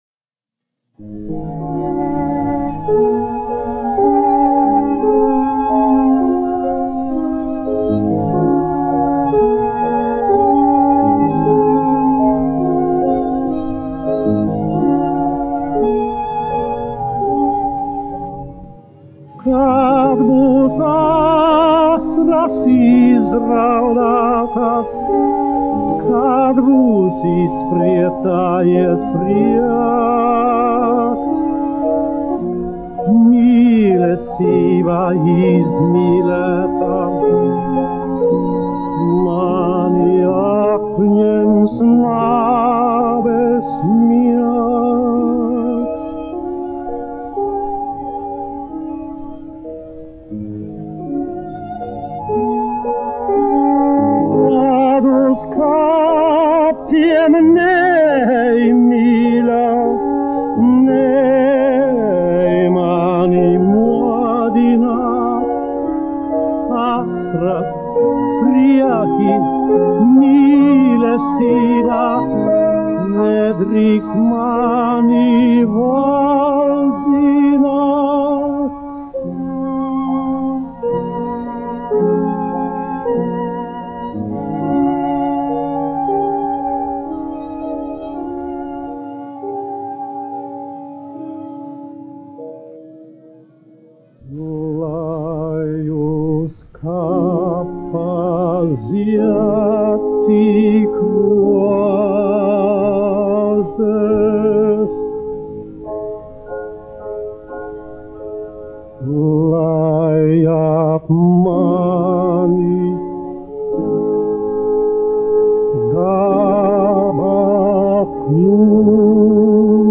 1 skpl. : analogs, 78 apgr/min, mono ; 25 cm
Dziesmas (augsta balss) ar instrumentālu ansambli
Skaņuplate